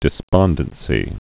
(dĭ-spŏndən-sē)